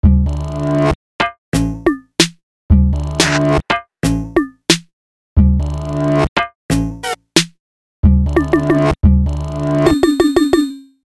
打木头 " 打木头 07
描述：一声木响。 用Tascam DR40录制。
标签： 冲击 木材 打击乐 自来水 敲打 冲击
声道立体声